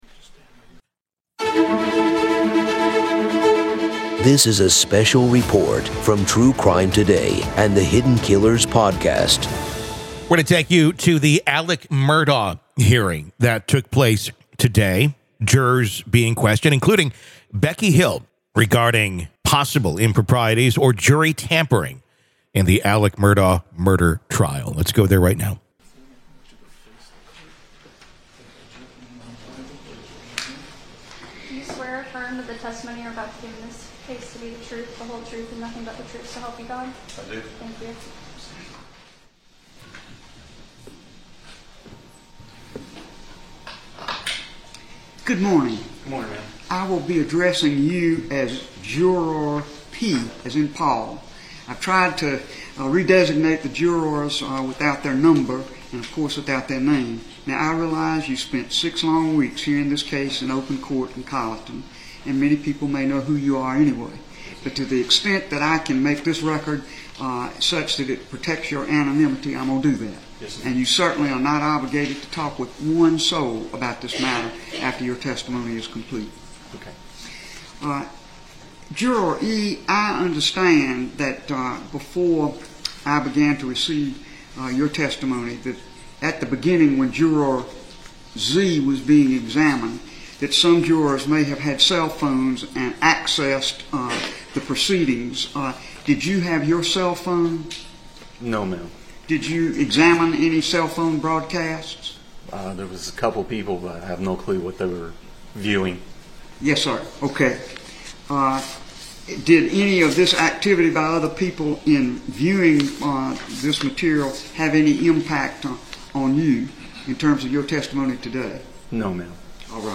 Court Audio-Part 2-Judge Denies Alex Murdaugh's Bid for New Trial Amid Jury Tampering Allegations
In a daylong evidentiary hearing, Judge Jean Toal has denied convicted murderer Alex Murdaugh's request for a new trial. The hearing centered on allegations that Colleton County Clerk of Court Rebecca Hill had tampered with the jury and tainted the verdict.